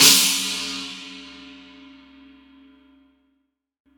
Ride Sound Clip C# Key 01.wav
Royality free ride sound clip tuned to the C# note. Loudest frequency: 5856Hz
ride-sound-clip-c-sharp-key-01-1aC.ogg